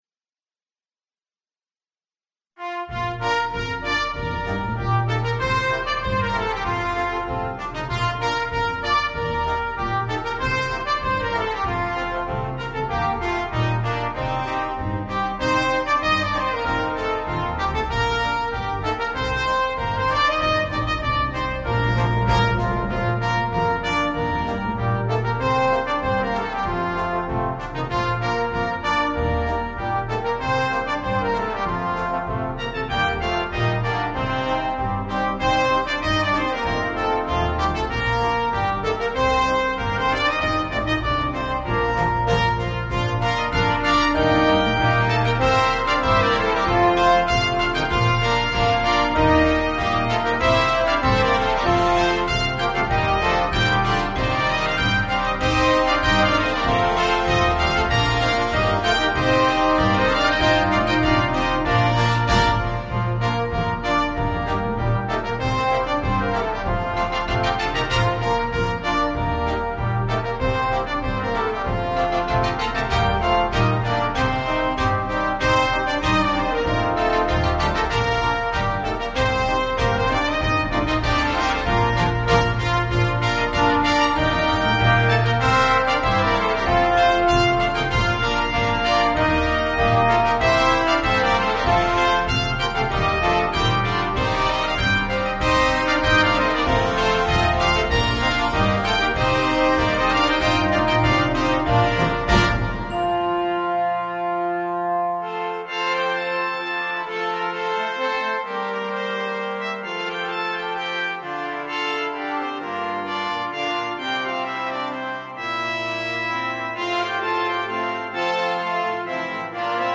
The fifth arrangement of Australian Folksongs for Brass Band
Folk and World